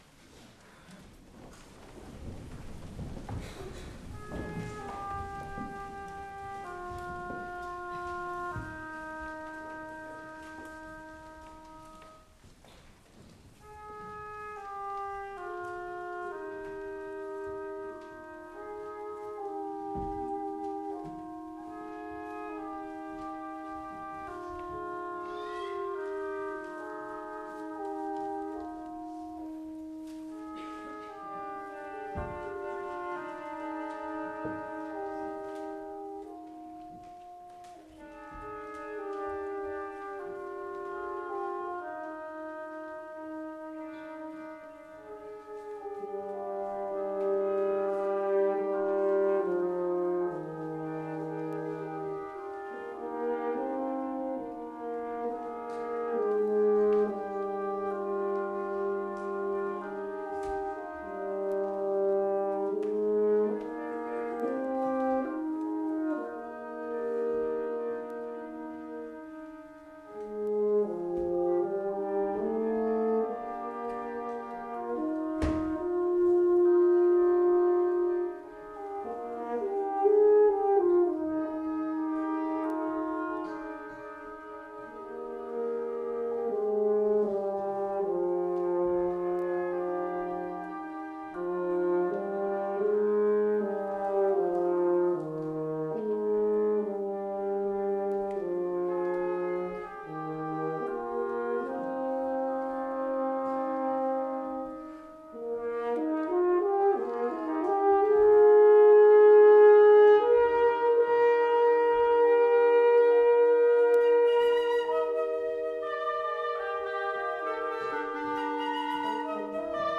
for Woodwind Quintet (1988)